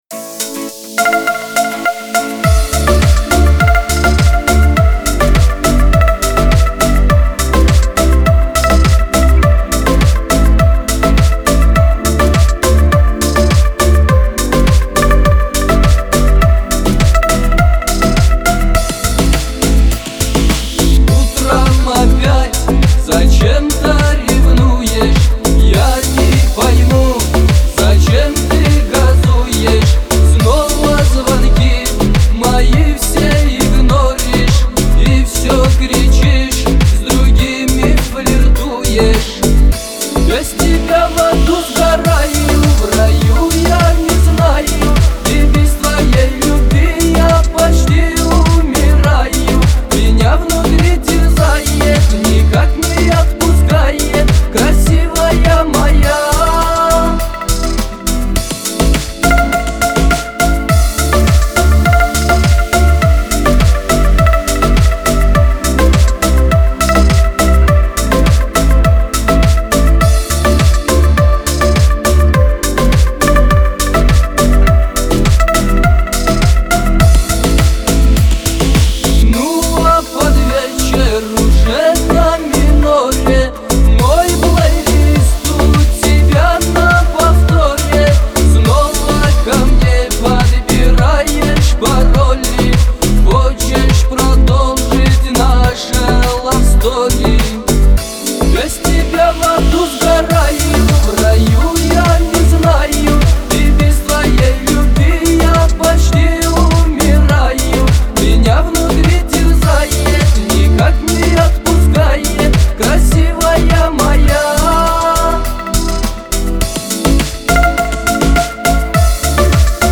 Кавказ поп , грусть
Лирика